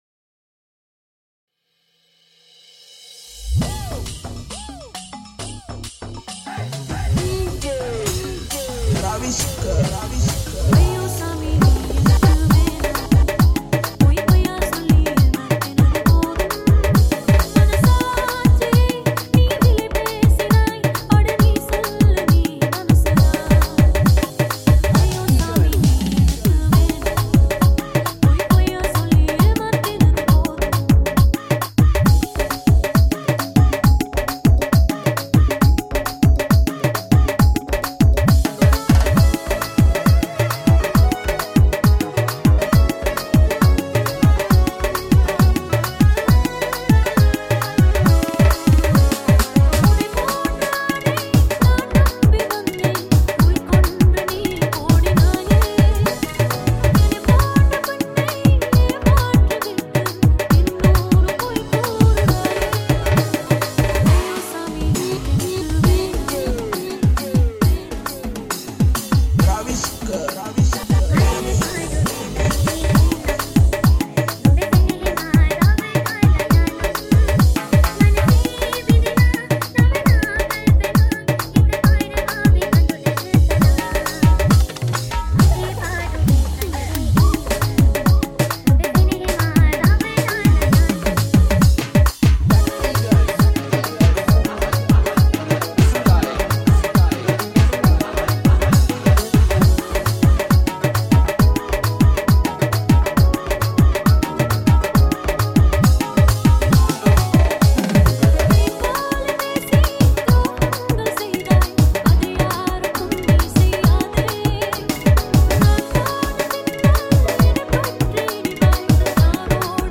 High quality Sri Lankan remix MP3 (2.3).